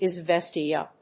音節Iz・ves・tia 発音記号・読み方
/ɪzvéstjə(米国英語), ɪˈzvesti:ʌ(英国英語)/